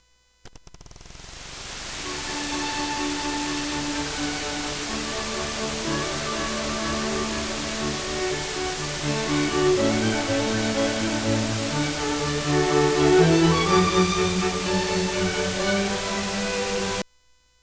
The problem was audio source 192KHz 24-bit I2S to line out with audio DSP will hears noise and Without DSP sound is good.
Audio noise